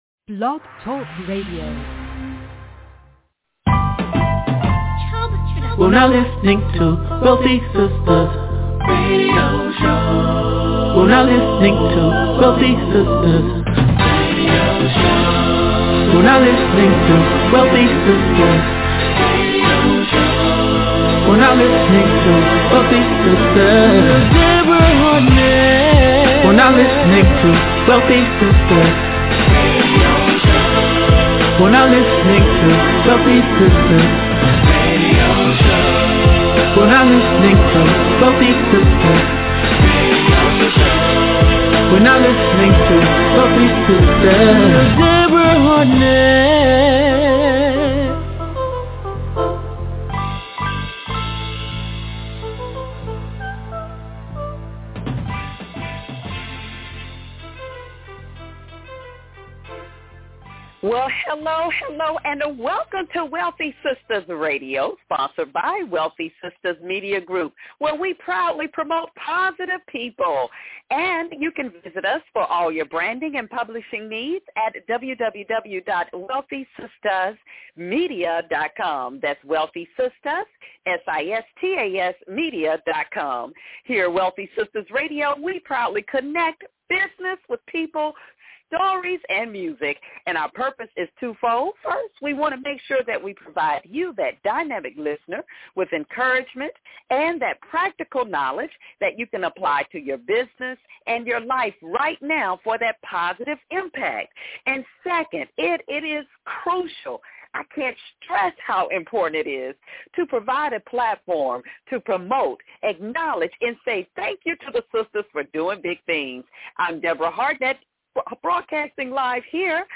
And is intertwined with some of the greatest R&B music of all time.